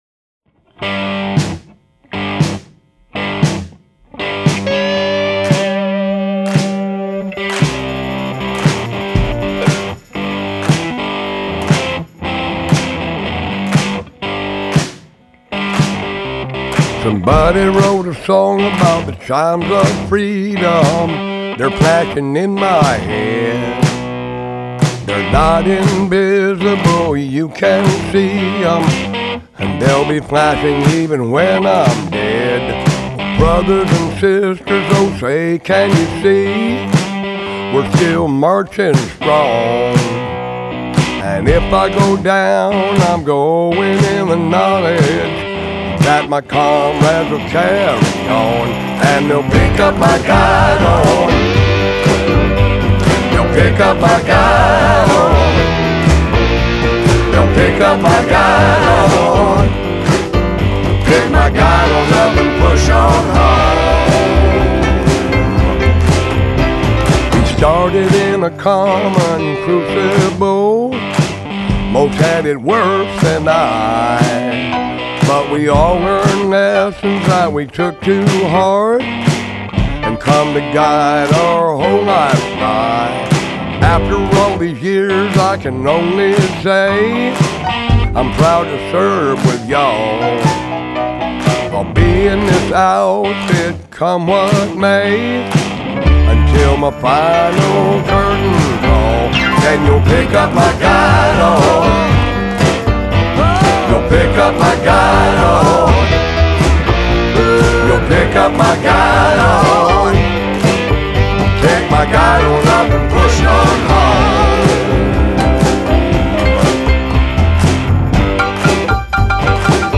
vocals/harmonica/slide guitar
upright bass